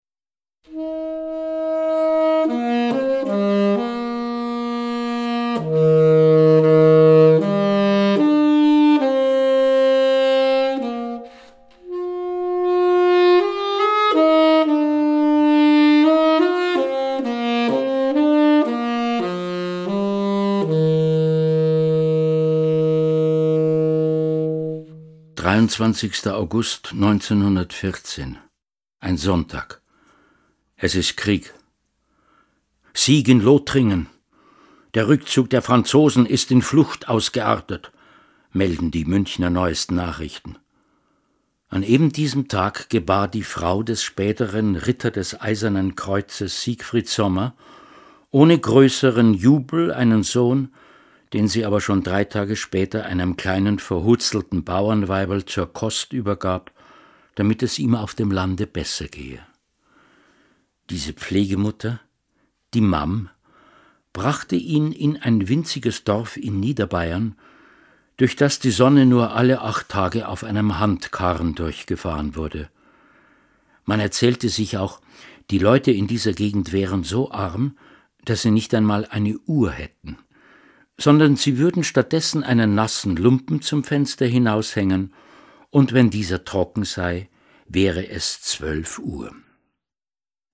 mal augenzwinkernd, mal ironisch, mal spöttisch, aber auch einfühlsam und nachdenk-lich.
mit dem Saxophon und Musikstücken der jeweiligen Zeit sowie eigenen Kompositionen
Schlagworte 20er Jahre • Blasius • Hörbuch; Literaturlesung • Kriegstagebuch • Moderne und zeitgenössische Belletristik • München • Nachkriegszeit • Zweiter Weltkrieg